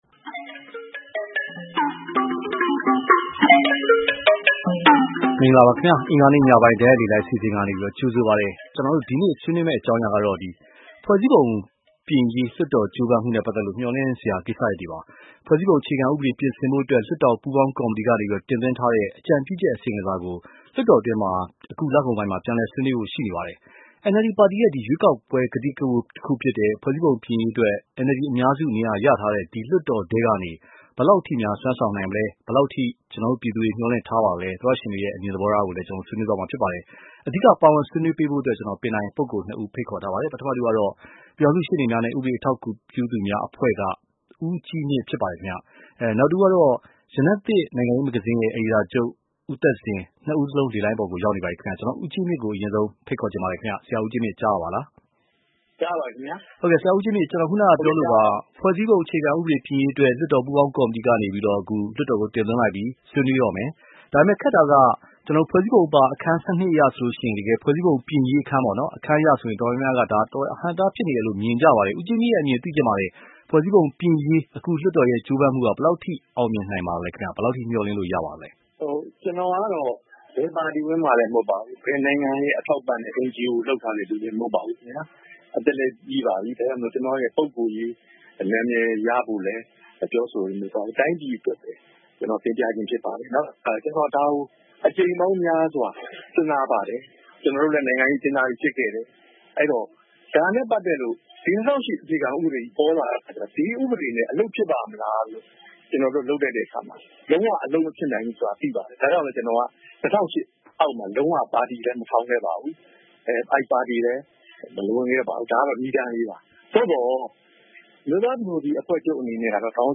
ဖွဲ့စည်းပုံပြင်ရေးလွှတ်တော်ကြိုးပမ်းမှု မျှော်လင့်စရာ (တိုက်ရိုက်လေလှိုင်း)